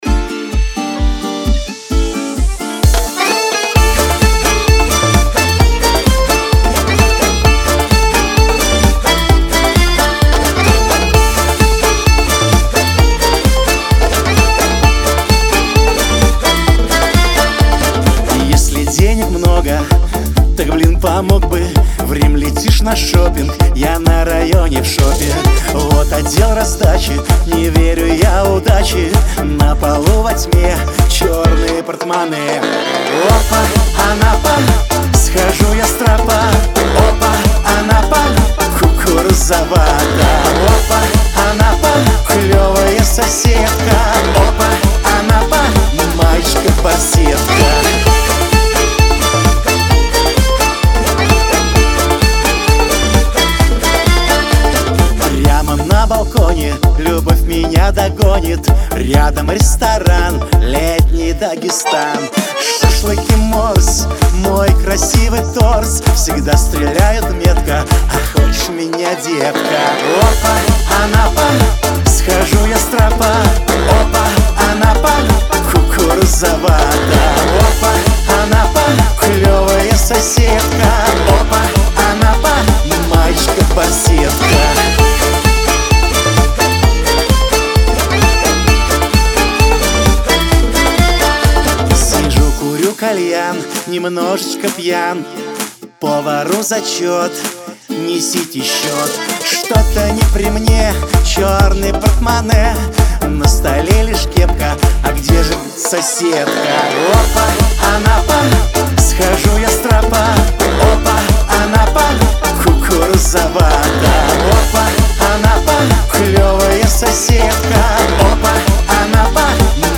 tancevalnaja_sskaja_muzika___opa_anapa_.mp3